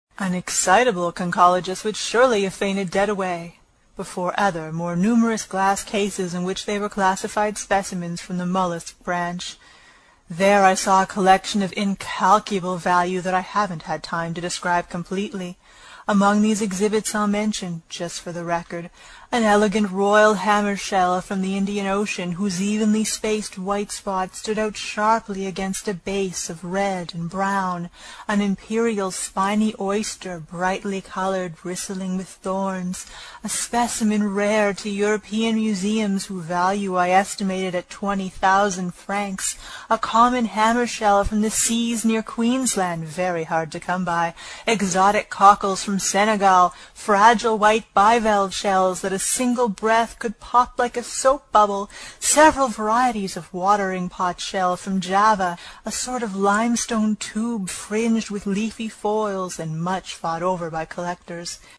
在线英语听力室英语听书《海底两万里》第162期 第11章 诺第留斯号(13)的听力文件下载,《海底两万里》中英双语有声读物附MP3下载